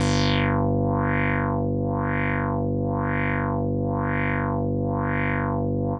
Index of /90_sSampleCDs/Trance_Explosion_Vol1/Instrument Multi-samples/LFO Synth
G2_lfo_synth.wav